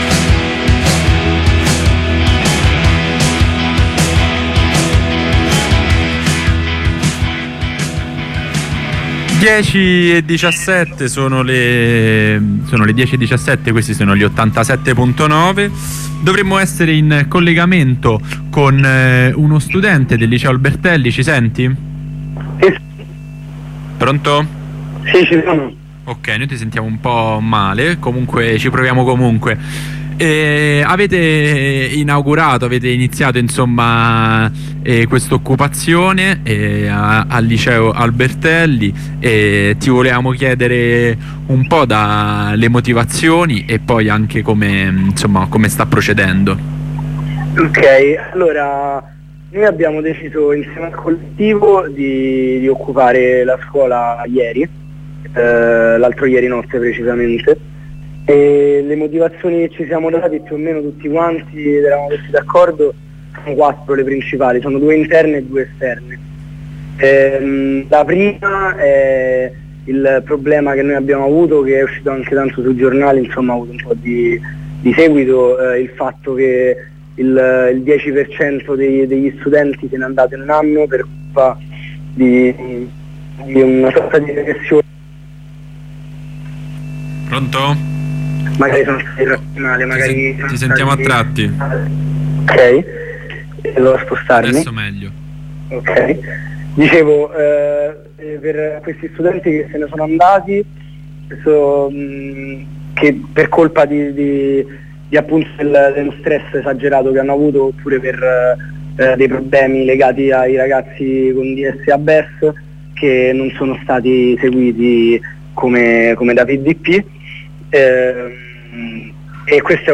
Intervento dal presidio